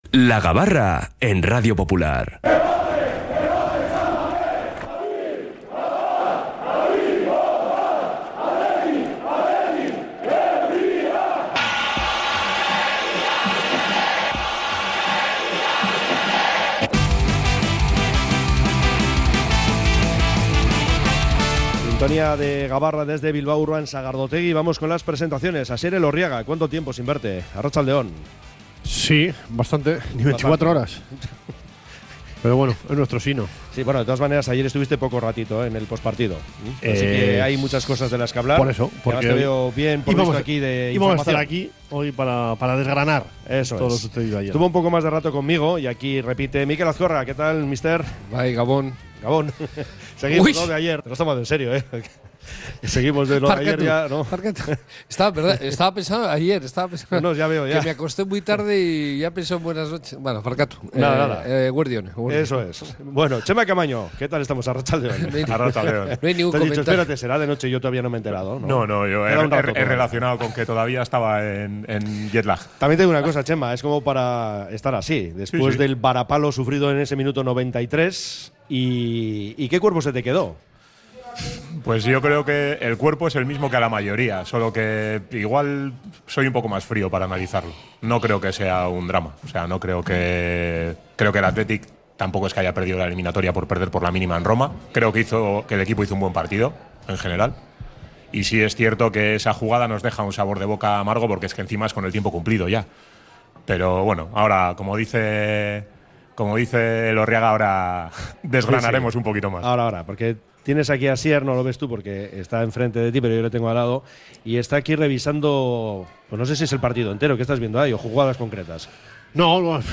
La Gabarra: La tertulia diaria del Athletic